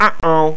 uhoh.mp3